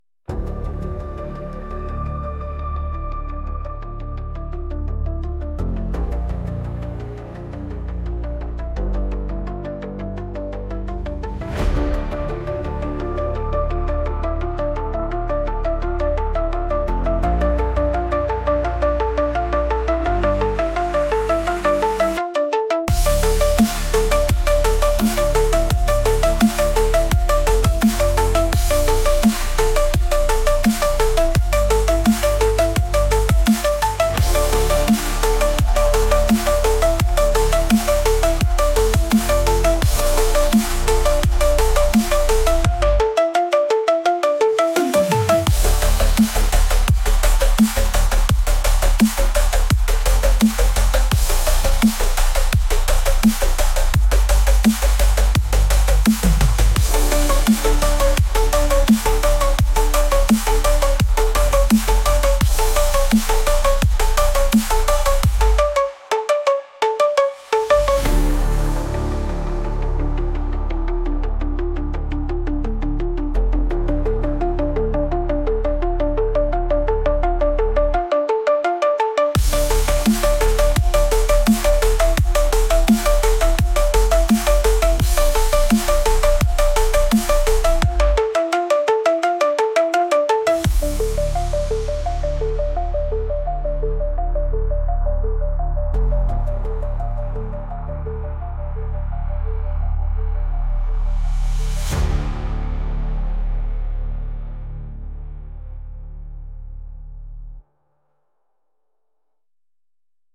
High‑Energy EDM Track